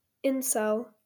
Incel (/ˈɪnsɛl/
IN-sel; a portmanteau of "involuntary celibate"[1]) is a term associated with an online subculture of mostly male and heterosexual[2] people who define themselves as unable to find a romantic or sexual partner despite desiring one.